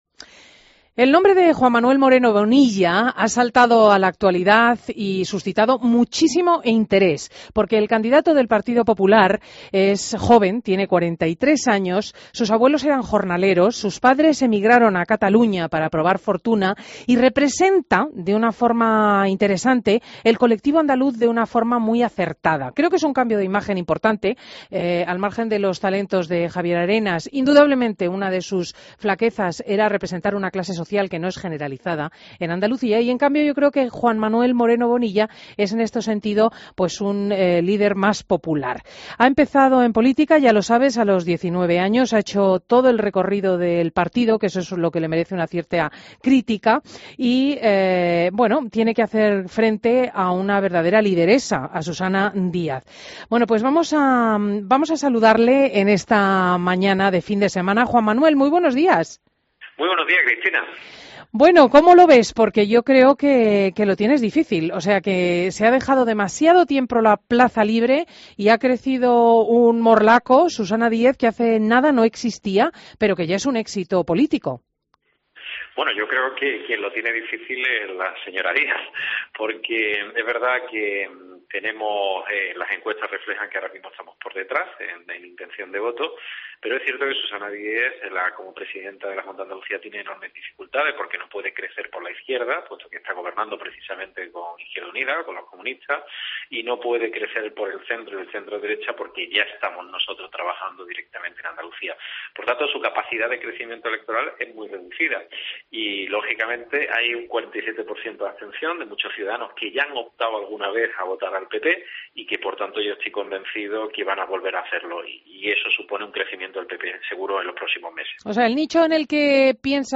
Entrevista a Juan Manuel Moreno Bonilla en Fin de Semana COPE